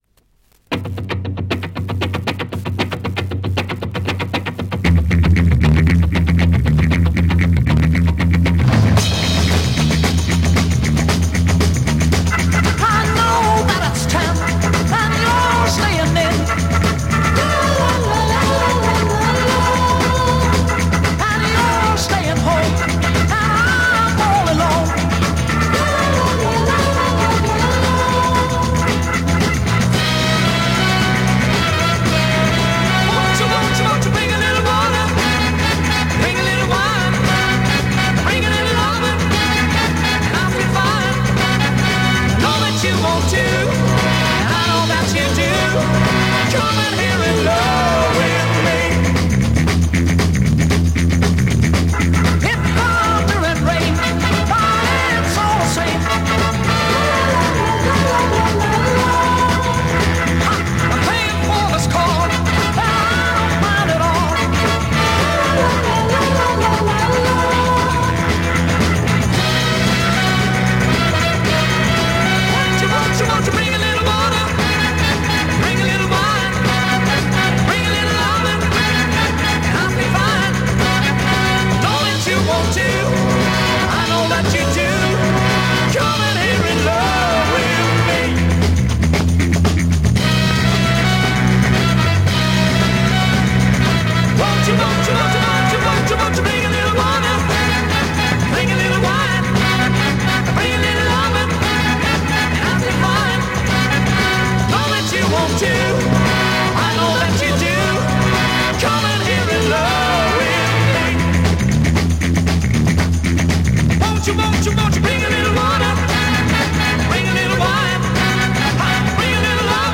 Spanish Freakbeat Mod Killer
Classic and essentiel Mod freakbeat smasher!